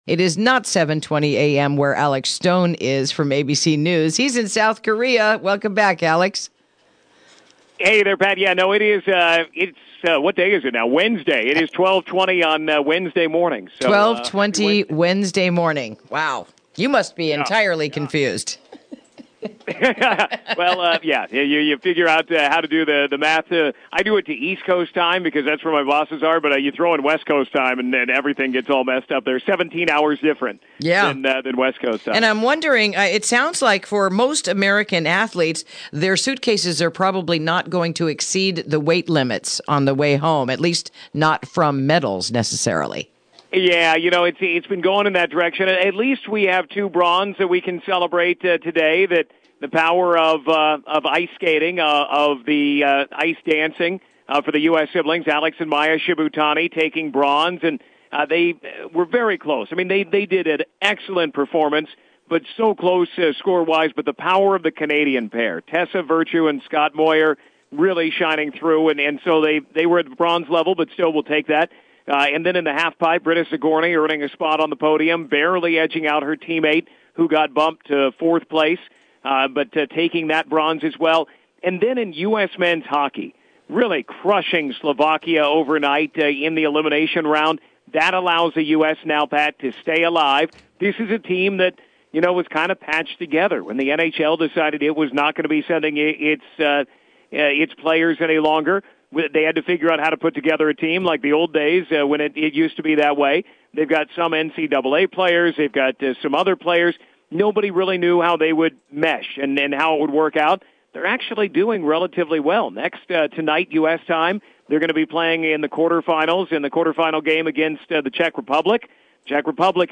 Interview: Day 11 of the 2018 Winter Olympics